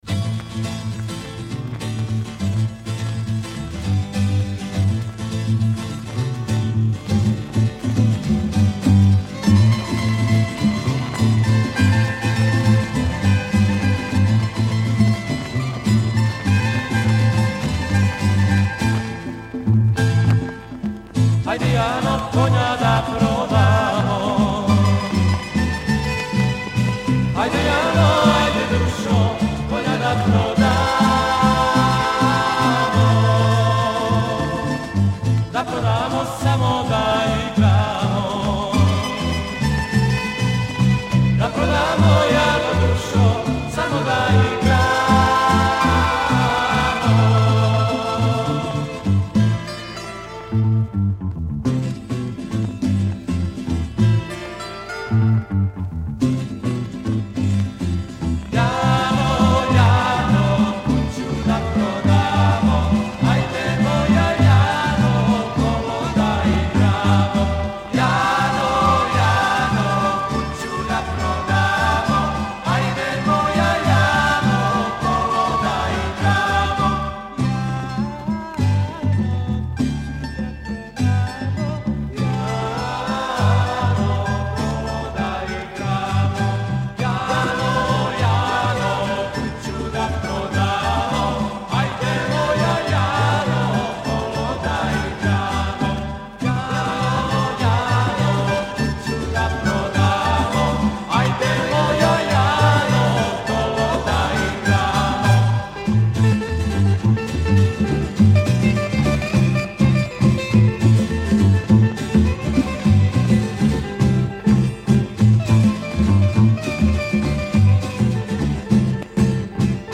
Genre:Rock, Pop, Folk, World, & Country
Style:Rock, Ballad